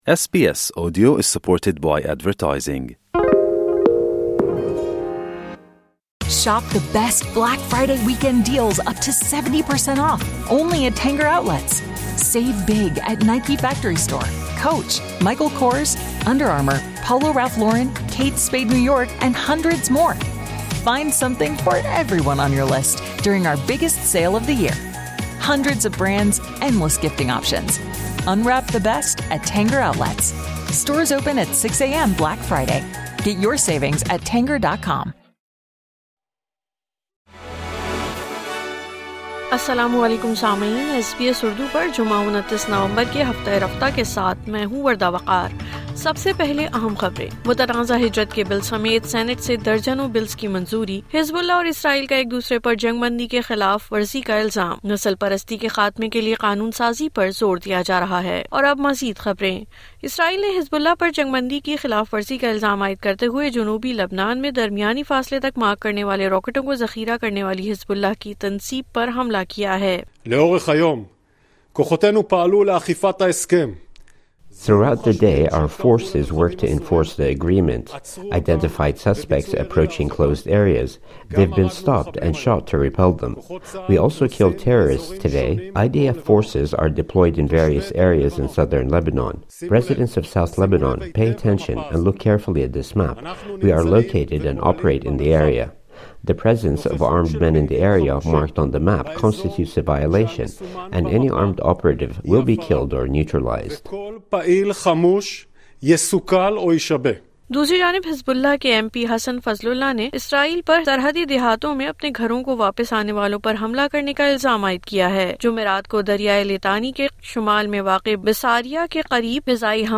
ہفتہ رفتہ 29 نومبر 2024:پورے ہفتے کی خبروں کا خلاصہ